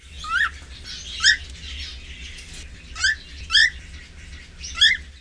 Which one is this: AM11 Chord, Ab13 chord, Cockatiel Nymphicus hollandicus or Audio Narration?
Cockatiel Nymphicus hollandicus